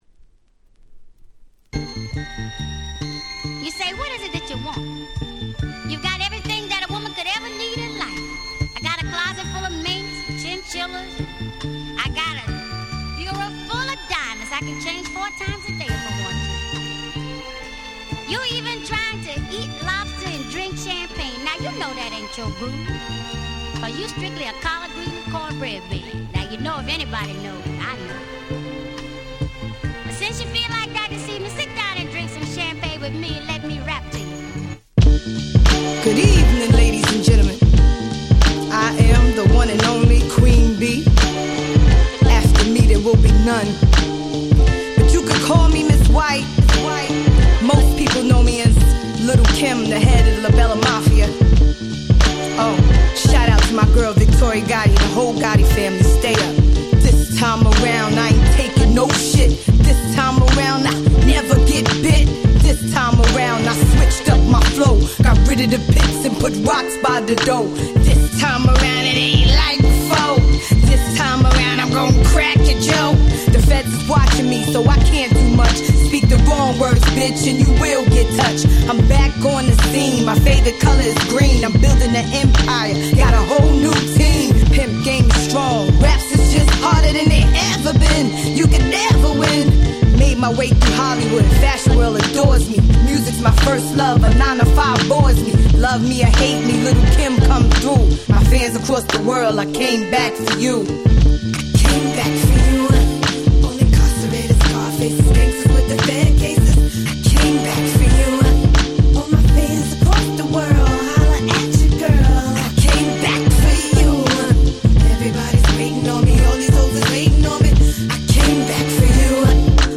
02' Smash Hit Hip Hop !!